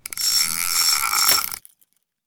fishReelIn.wav